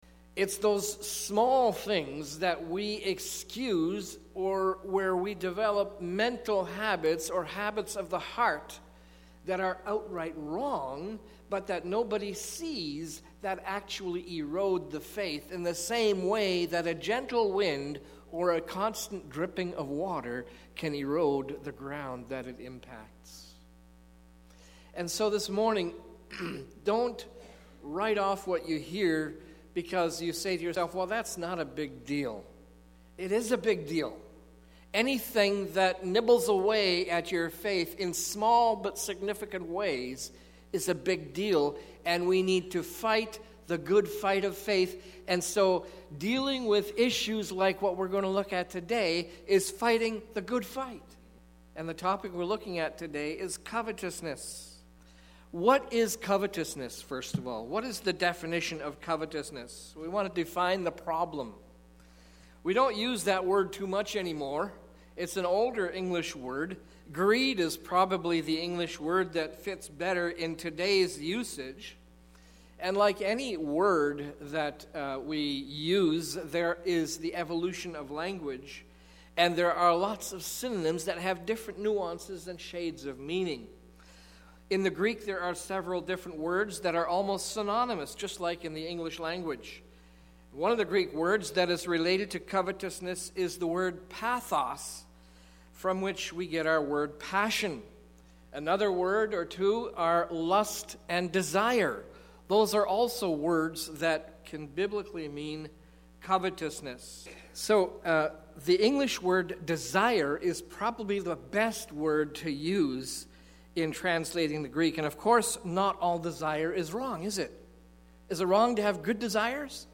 How does something like covetousness, which seems so insignificant, destroy our faith? In this message, the Pastor uses John Piper's material found in Future Grace to show how desires can get out of hand and destroy the vitality of our faith.